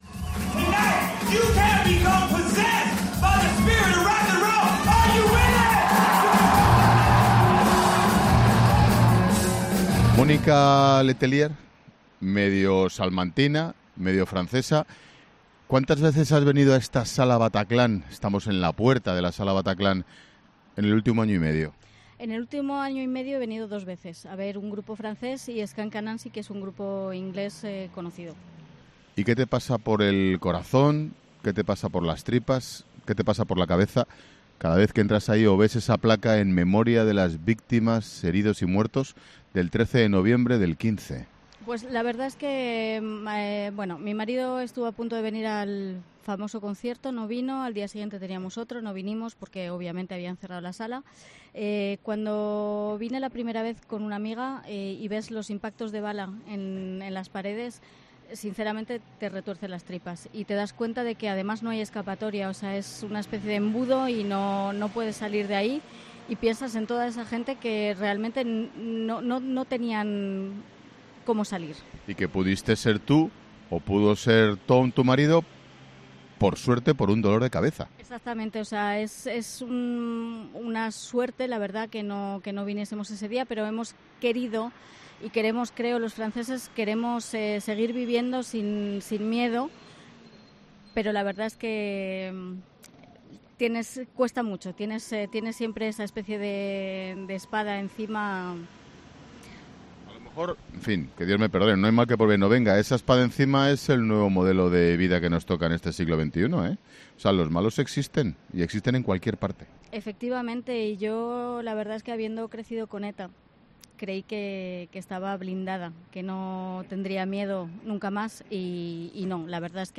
La discoteca Bataclan se convirtió en una auténtica “sala de los horrores” cuando el 13 de noviembre de 2015 fue atacada por los terroristas islámicos. 'La Tarde' de Expósito se emite este lunes desde París con motivo de las elecciones francesas.